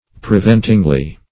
preventingly - definition of preventingly - synonyms, pronunciation, spelling from Free Dictionary Search Result for " preventingly" : The Collaborative International Dictionary of English v.0.48: Preventingly \Pre*vent"ing*ly\, adv. So as to prevent or hinder.